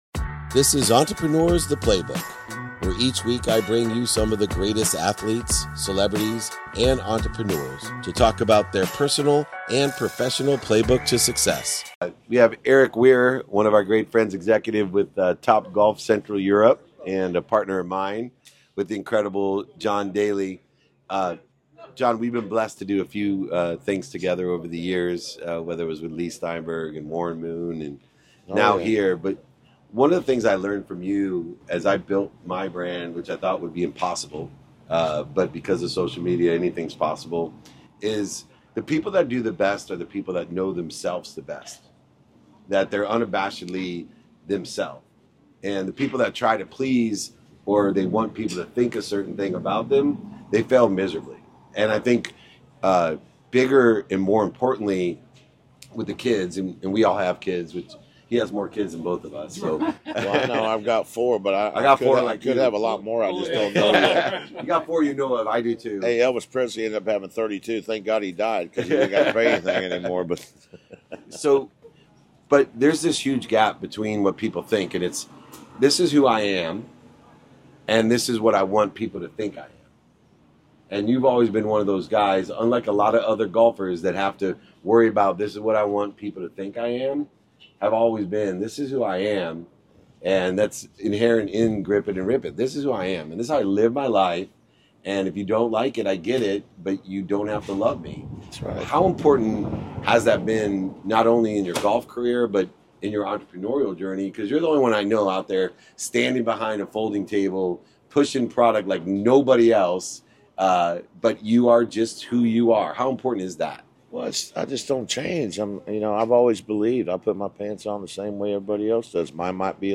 In today's episode, we join legendary golfer John Daly for a fireside chat in Augusta.